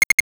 NOTIFICATION_8bit_06_mono.wav